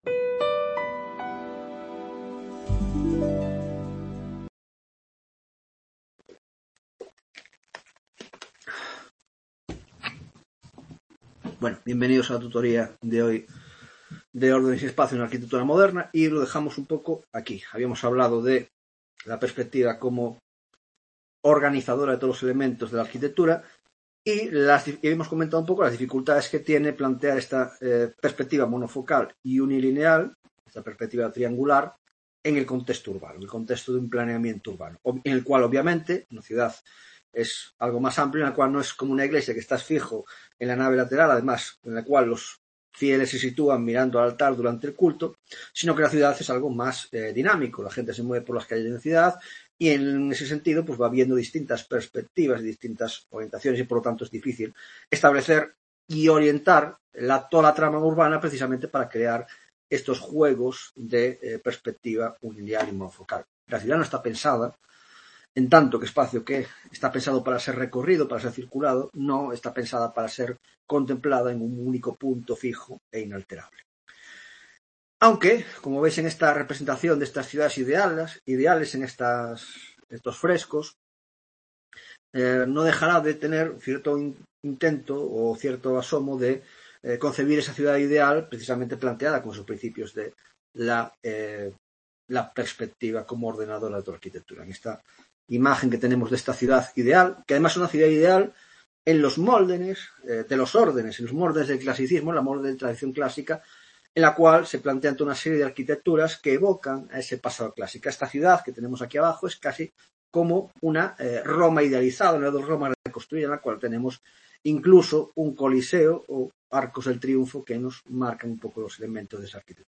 2ª Tutoria de Órdenes y Espacio en la Arquitectura Moderna (grado de Historia del Arte): 1) El Espacio en la Arquitectura Moderna (2ª parte); 1) Manierismo; 2) Barroco y 3) Neoclasicismo